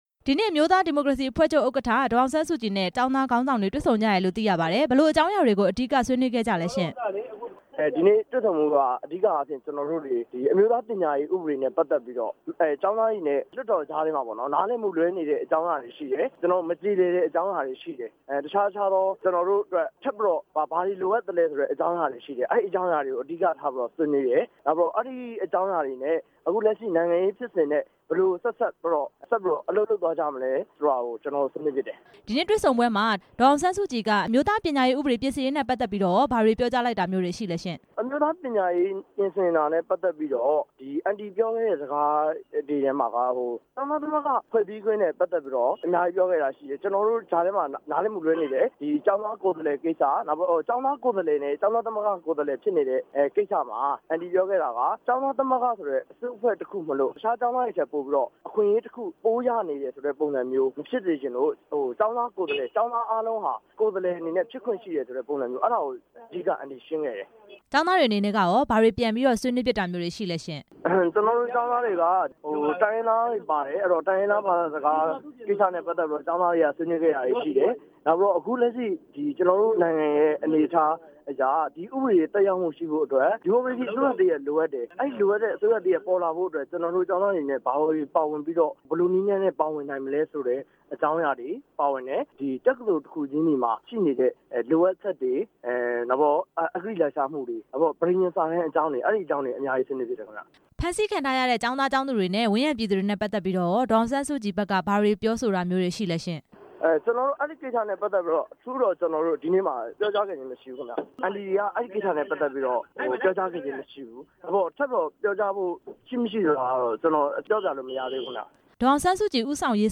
ဒေါ်အောင်ဆန်းစုကြည်နဲ့ ကျောင်းသူ ကျောင်းသားတွေ တွေ့ဆုံတဲ့အကြောင်း မေးမြန်းချက်